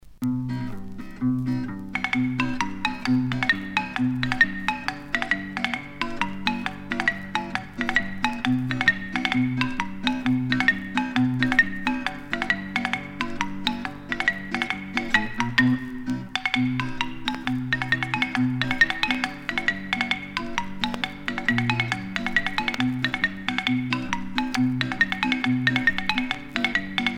verres percutés
Pièce musicale éditée